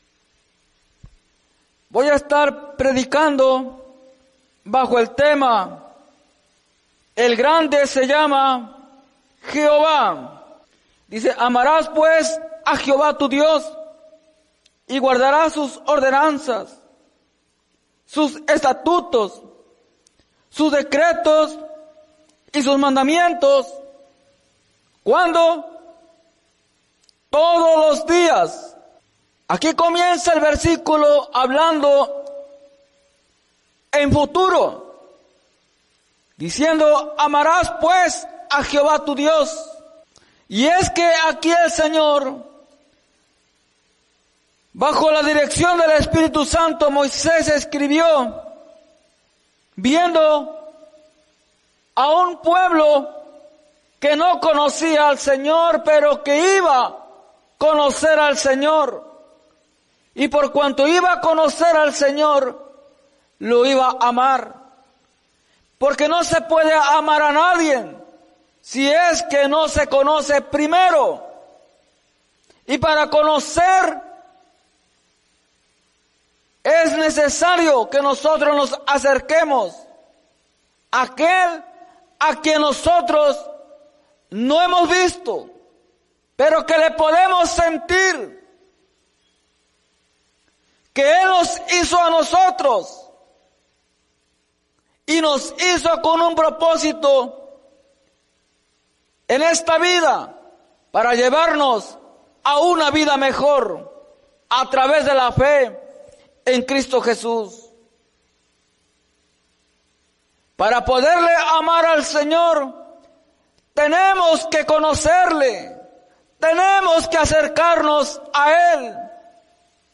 en la Iglesia Misión Evangélica en Norristown, PA